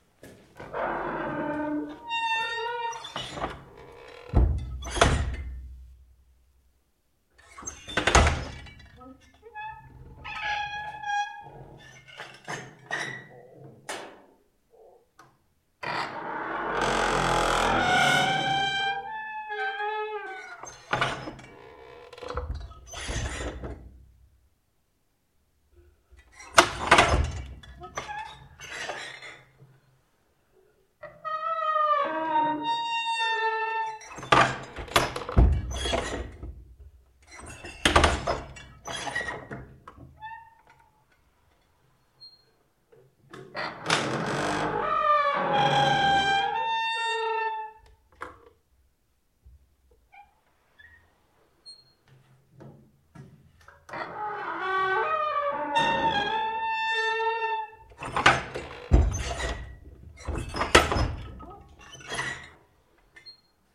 the door that sqeueks